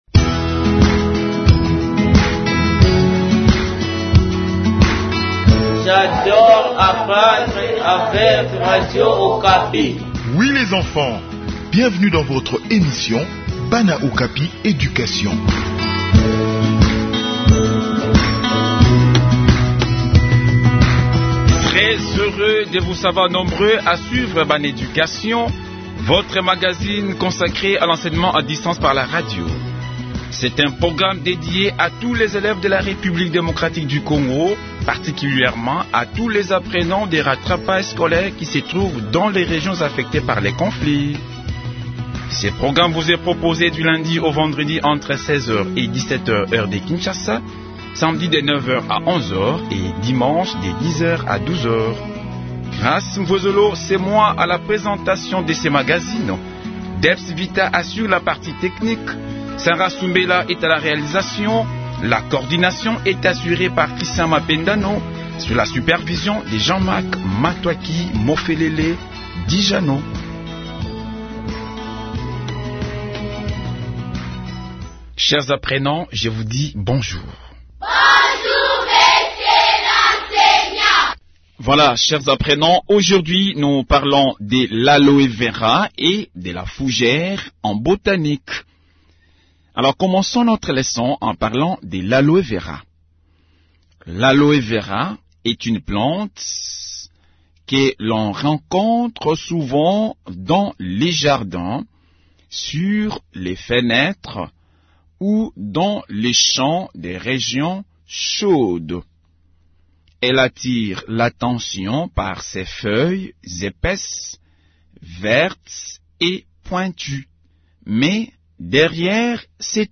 Leçon sur l’aloé vera et la fougère